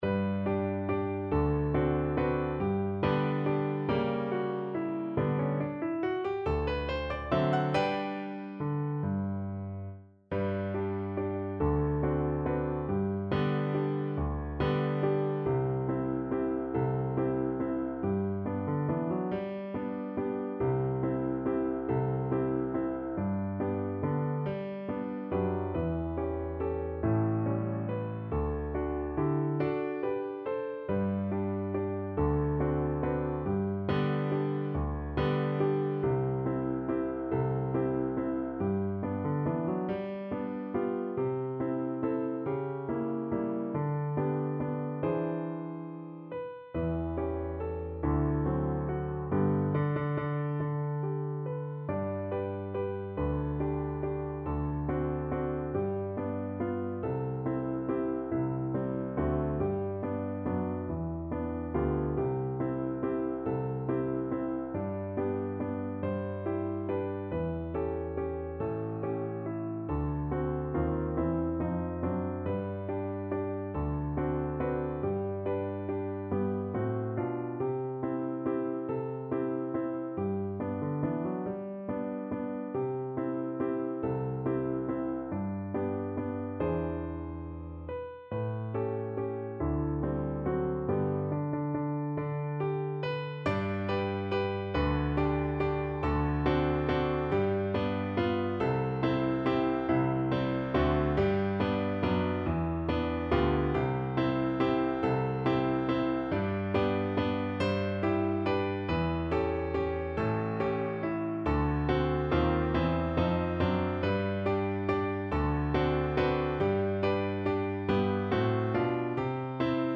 Time Signature: 3/4
Tempo di Valse